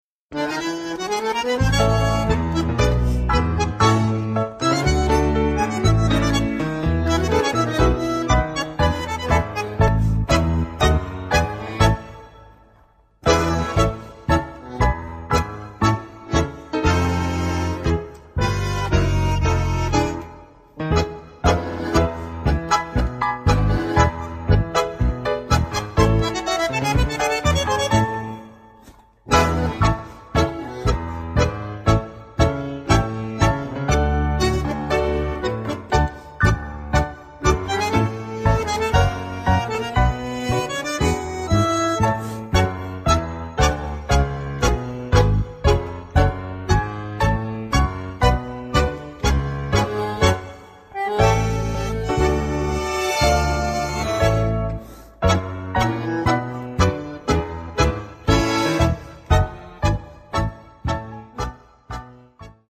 Audio Karaoke